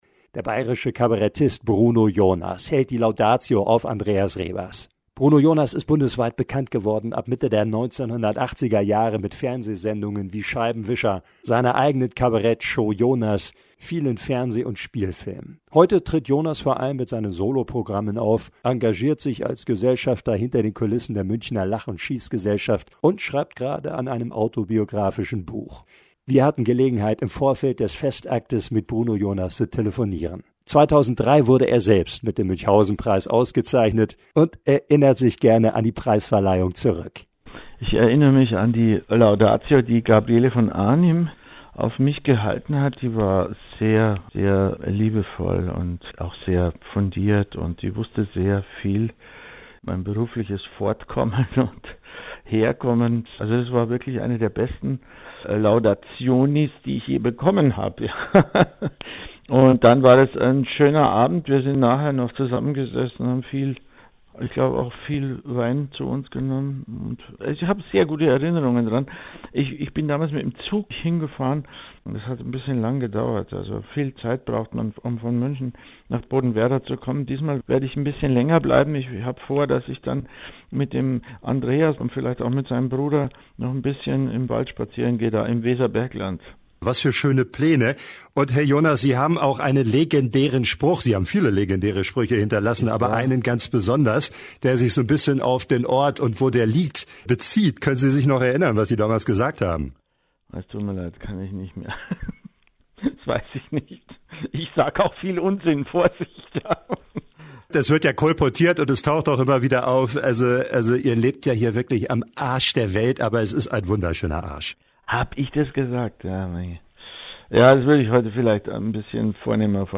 Bodenwerder: Kabarettist Bruno Jonas hält die Laudatio auf Münchhausenpreisträger Andreas Rebers – radio aktiv
bodenwerder-kabarettist-bruno-jonas-haelt-die-laudatio-auf-muenchhausenpreistraeger-andreas-rebers.mp3